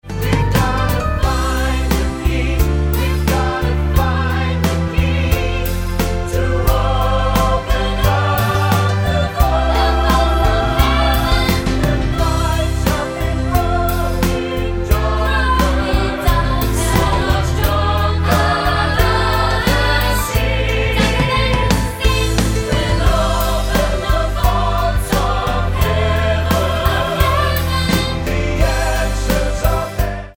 Tonart:C mit Chor